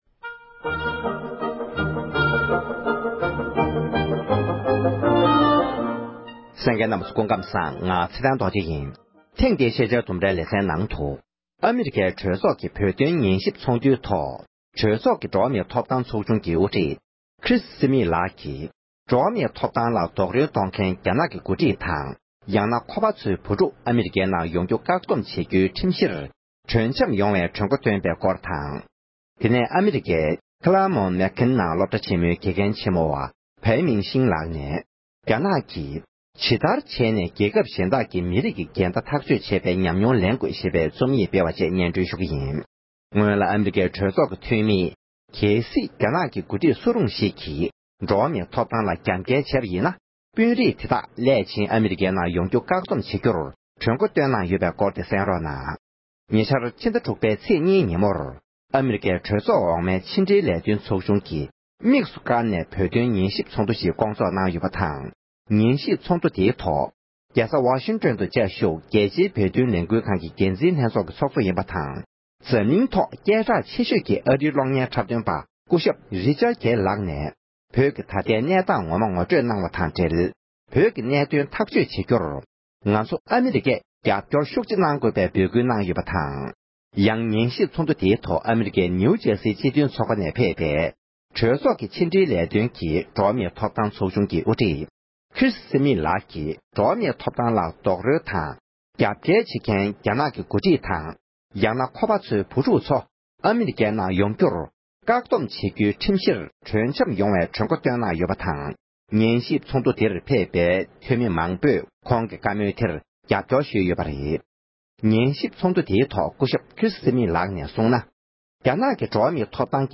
ཨ་རིའི་གྲོས་ཚོགས་ཀྱི་བོད་དོན་ཉན་ཞིབ་ཚོཊ་འདུའི་ཐོག་གྲོས་ཚོཊ་ཀྱི་འགྲོ་བ་མིའི་ཐོབ་ཐང་ཚོཊ་ཆུང་གི་དབུ་ཁྲིད་ཀྱིས་གནང་བའི་གསུང་བཤད།